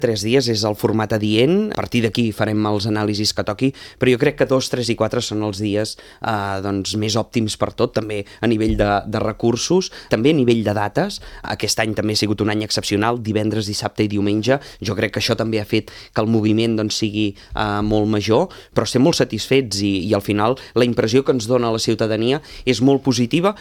El Campament Reial mantindrà el format de tres dies, malgrat les cues registrades per entregar les cartes als Patges Reials i participar en alguns tallers. Així ho ha dit el tinent d’Alcaldia d’Activitat Cultural, Josep Grima, a l’entrevista política de RCT.